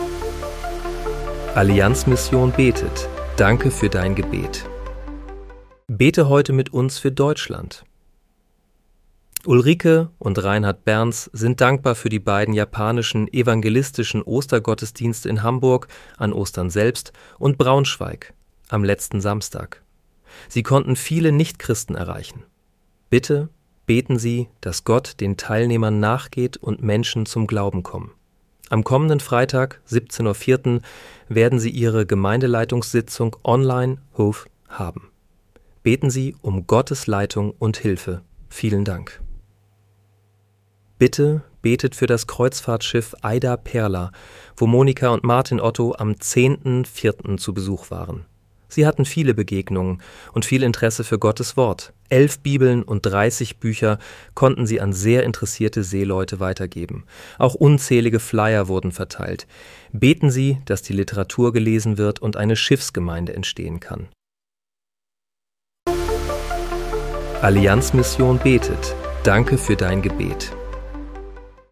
Bete am 17. April 2026 mit uns für Deutschland. (KI-generiert mit